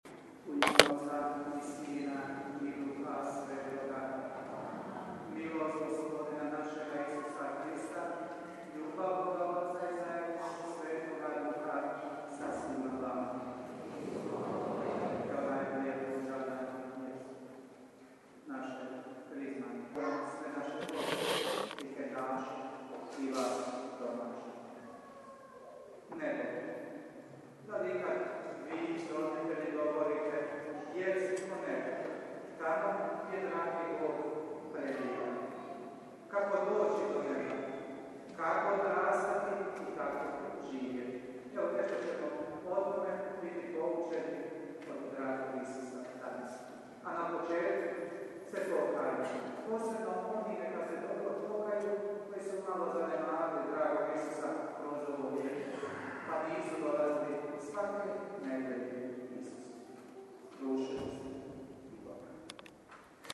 UVODNA MISAO